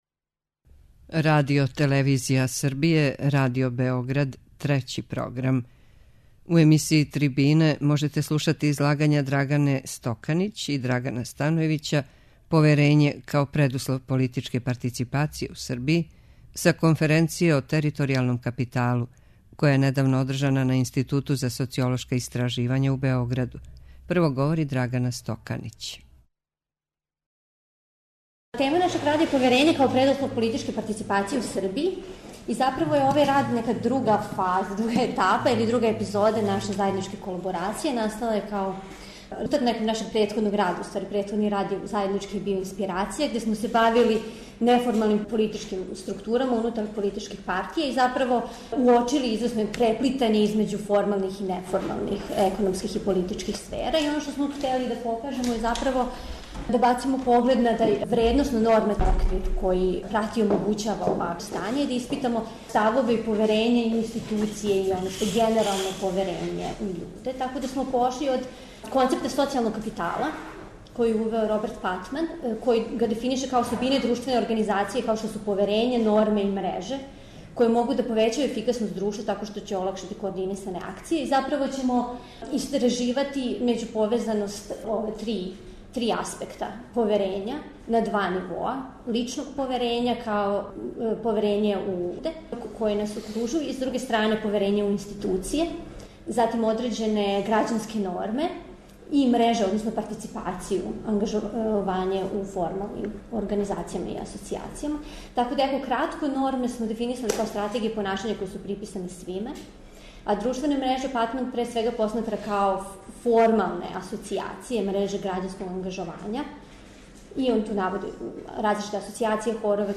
преузми : 8.19 MB Трибине и Научни скупови Autor: Редакција Преносимо излагања са научних конференција и трибина.